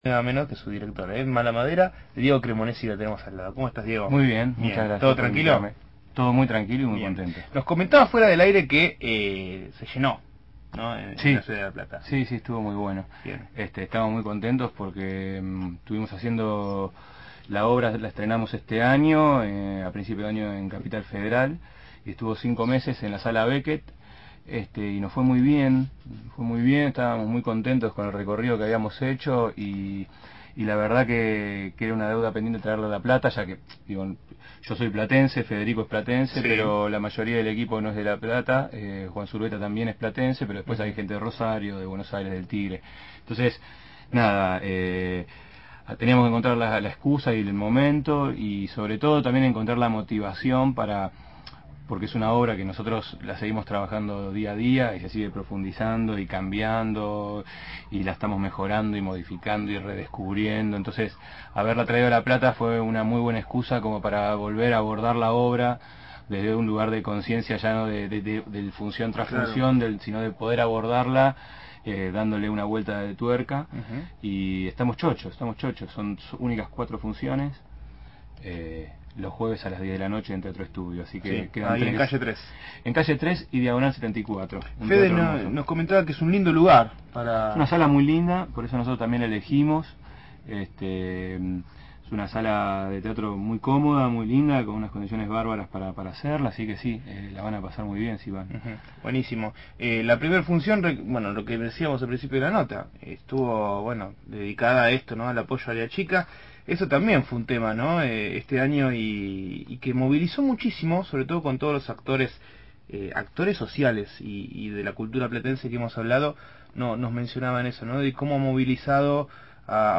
visitó el estudio de «Dame una señal»